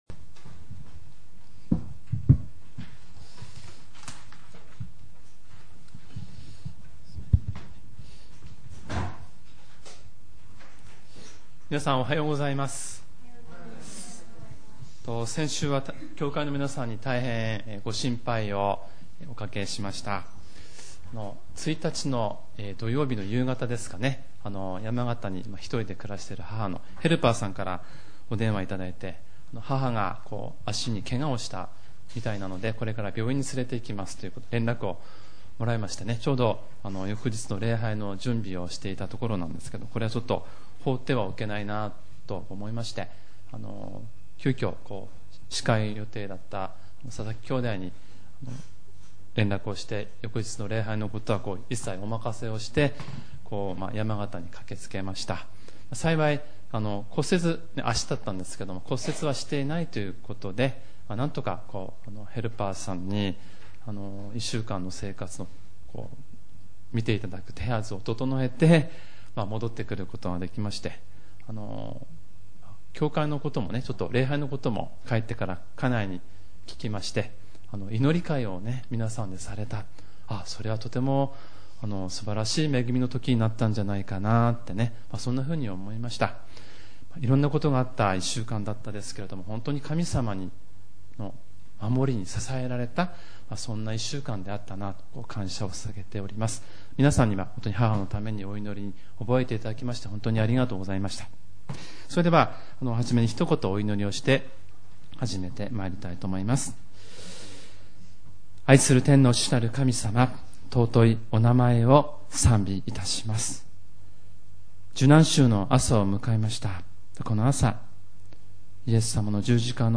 ●主日礼拝メッセージ（MP３ファイル、赤文字をクリックするとメッセージが聞けます）